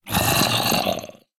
assets / minecraft / sounds / mob / zombie / say2.ogg